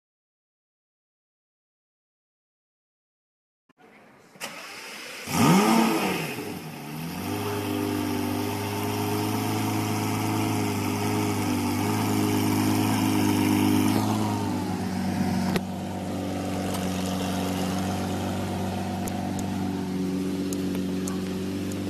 Sound Buttons: Sound Buttons View : Start Up Engine